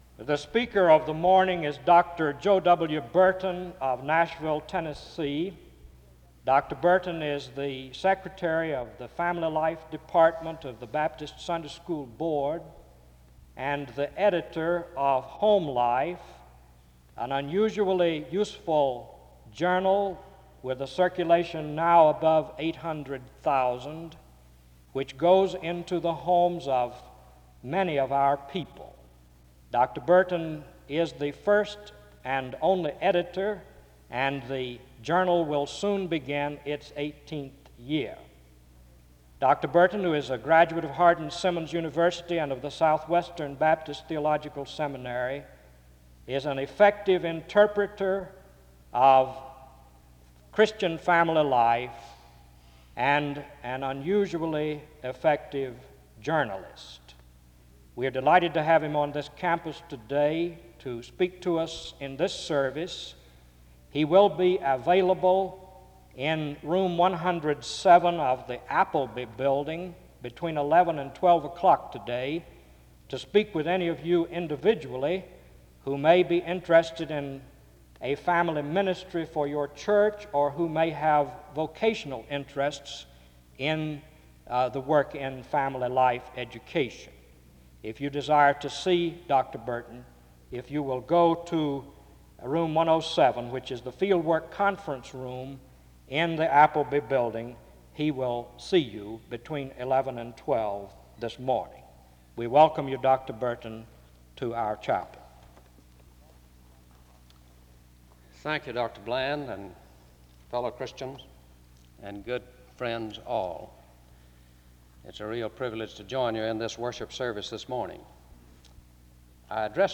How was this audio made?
The service begins with an introduction to the speaker from 0:00-1:45.